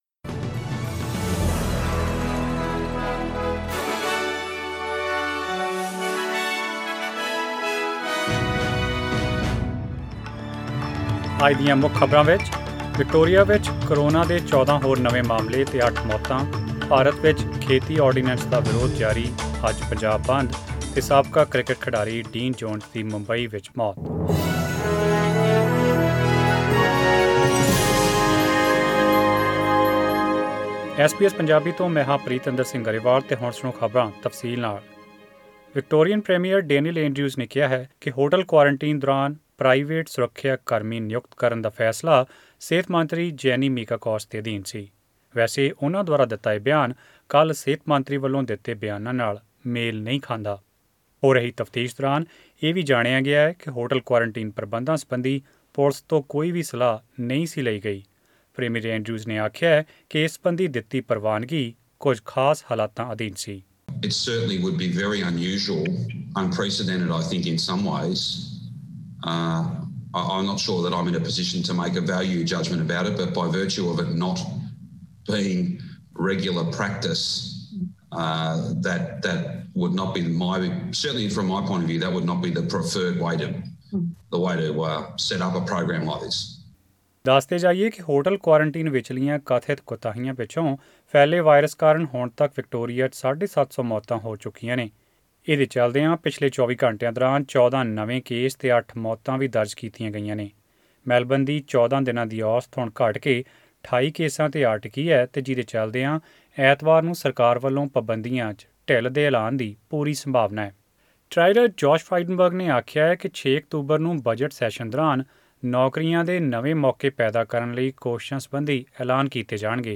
Australian News in Punjabi: 25 September 2020